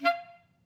Clarinet / stac
DCClar_stac_F4_v2_rr2_sum.wav